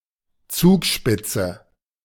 The Zugspitze (/ˈzʊɡʃpɪtsə/ ZUUG-shpit-sə,[4] German: [ˈtsuːkˌʃpɪtsə]
De-Zugspitze.ogg.mp3